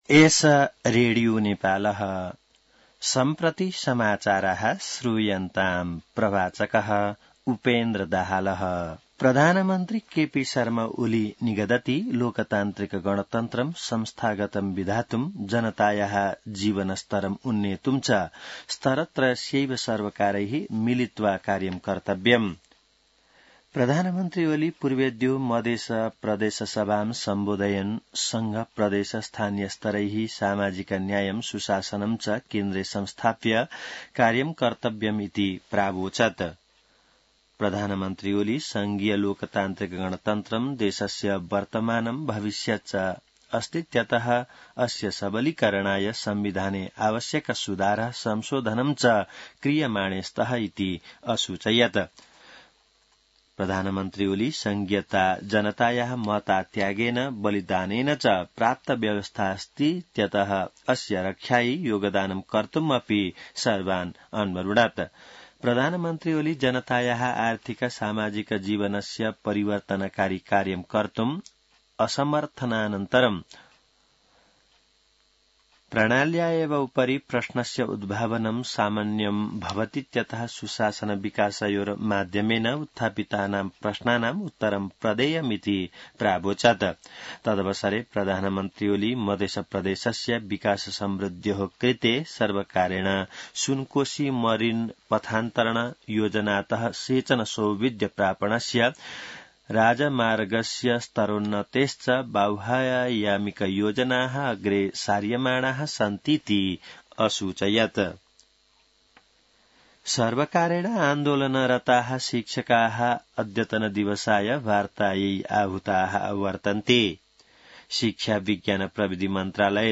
संस्कृत समाचार : २७ चैत , २०८१